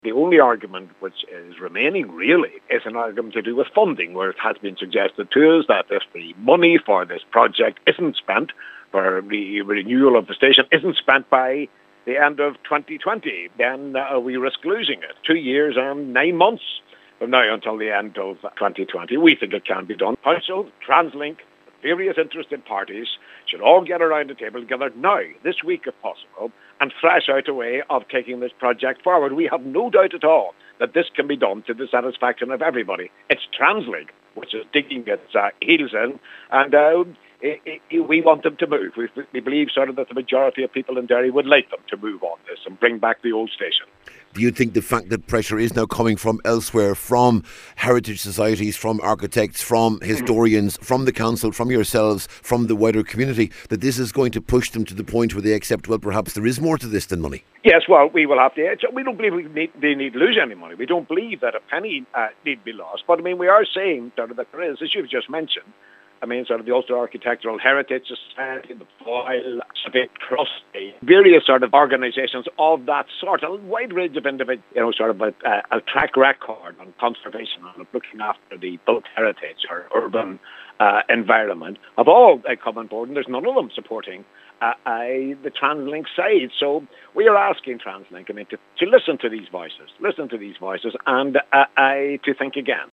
Into the West Spokesperson is Eamonn McCann: